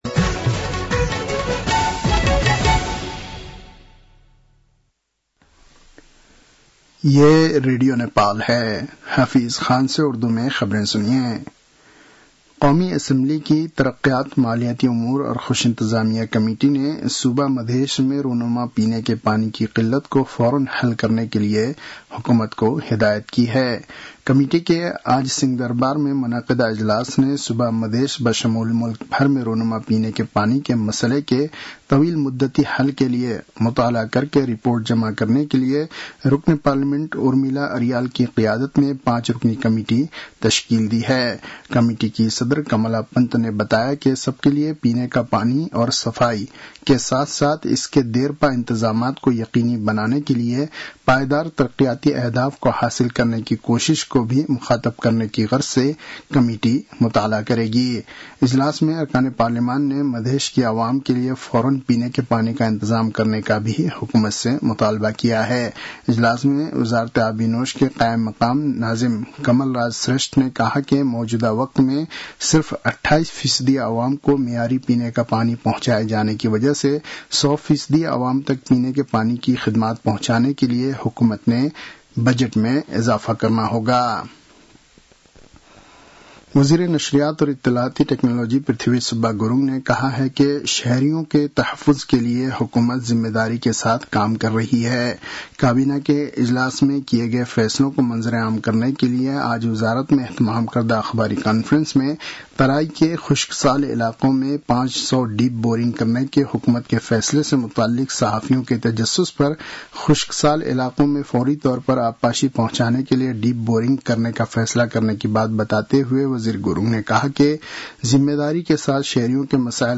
उर्दु भाषामा समाचार : १५ साउन , २०८२